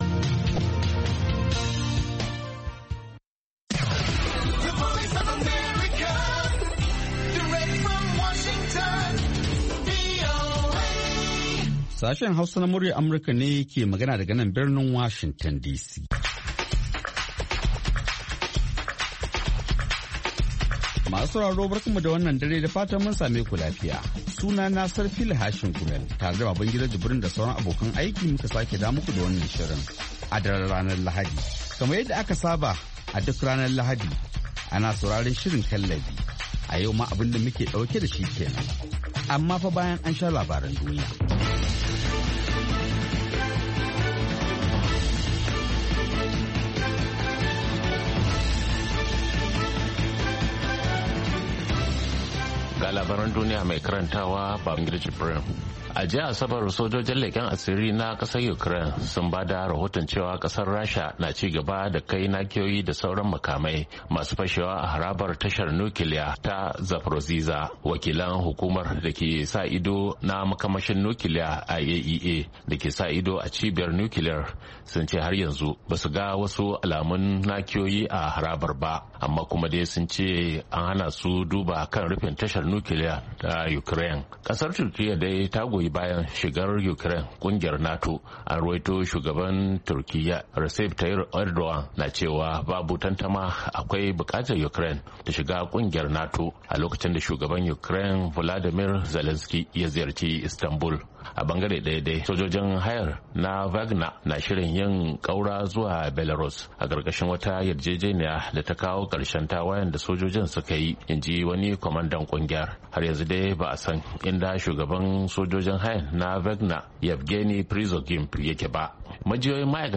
Kallabi wani sabon shiri ne kacokan kan mata, daga mata, a bakin mata, wanda Sashen Hausa na Muryar Amurka ya kirkiro don maida hankali ga baki daya akan harakokin mata, musamman a kasashenmu na Afrika. Shirin na duba rawar mata da kalubalensu ne a fannoni daban-daban na rayuwa.